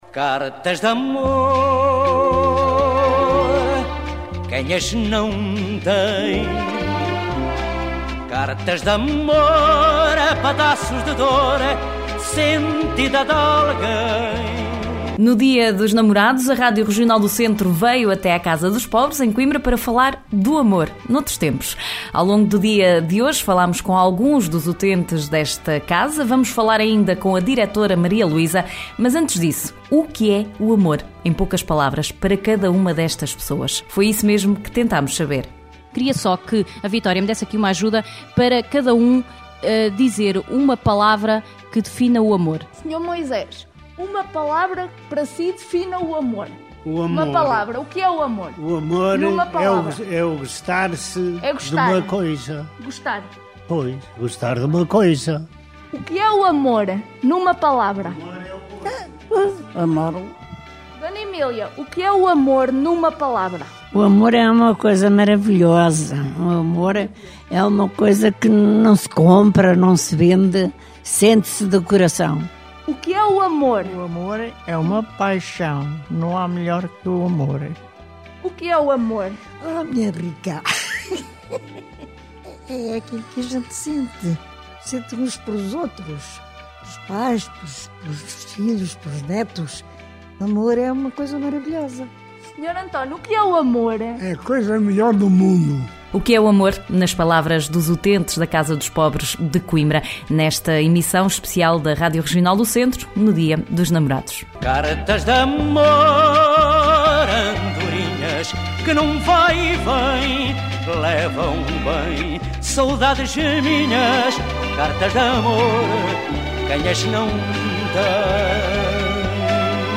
A Regional do Centro foi à Casa dos Pobres, em Coimbra, e perguntou a alguns utentes ‘O que é o Amor?’ em poucas palavras.